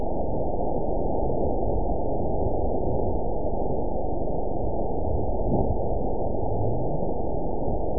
event 912529 date 03/28/22 time 20:29:32 GMT (3 years, 1 month ago) score 9.34 location TSS-AB05 detected by nrw target species NRW annotations +NRW Spectrogram: Frequency (kHz) vs. Time (s) audio not available .wav